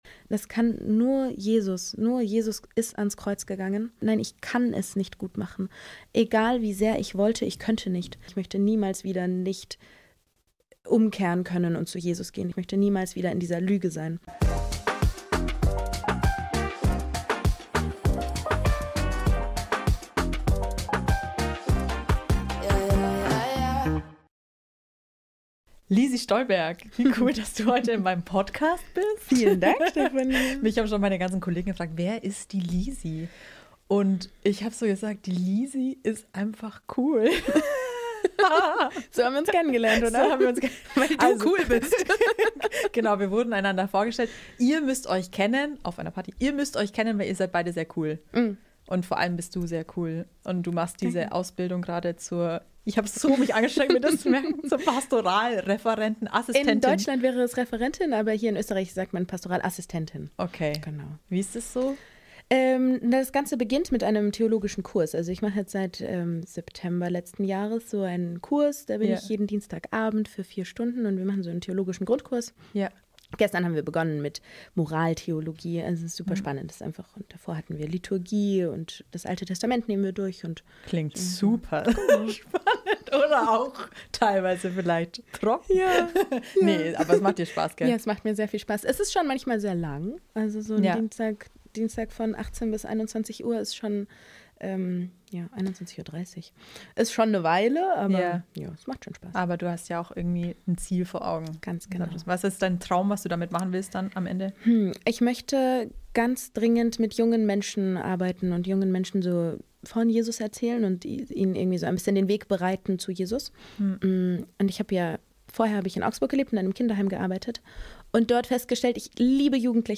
In diesem Gespräch erzählt sie, wie sie den Weg zurückgefunden hat und was ihr dabei geholfen hat: